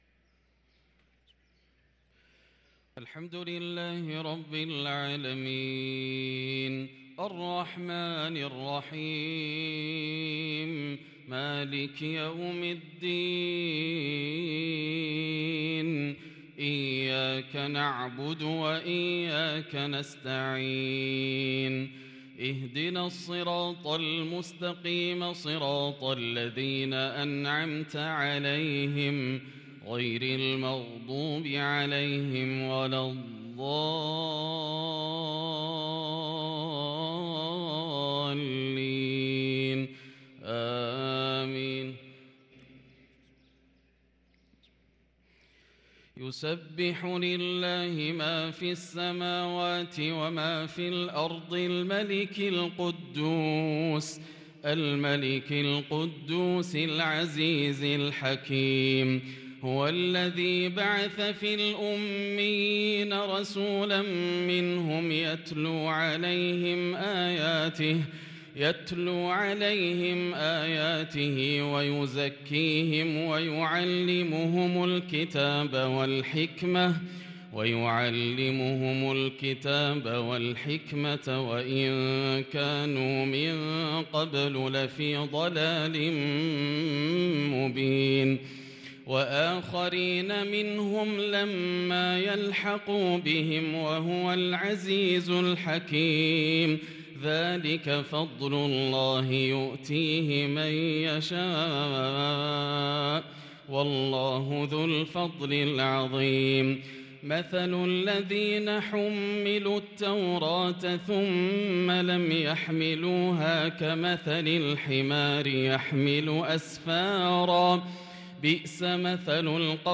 صلاة العشاء للقارئ ياسر الدوسري 5 جمادي الآخر 1444 هـ
تِلَاوَات الْحَرَمَيْن .